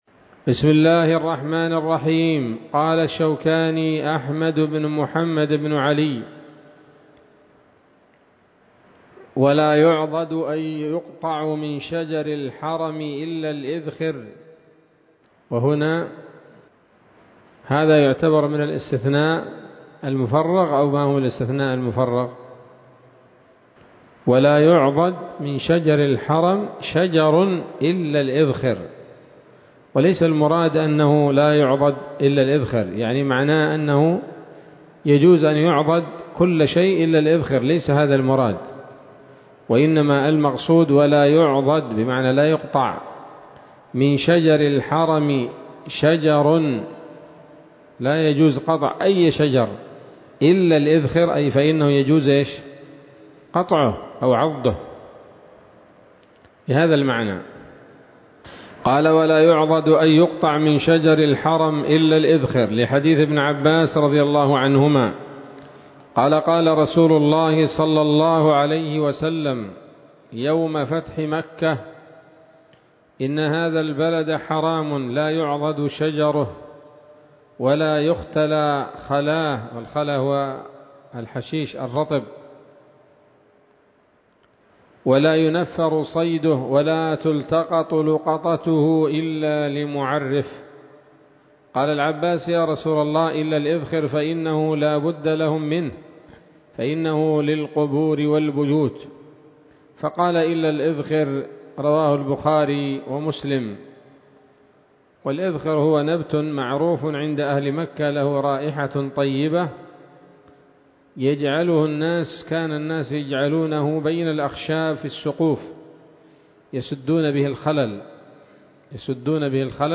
الدرس الحادي عشر من كتاب الحج من السموط الذهبية الحاوية للدرر البهية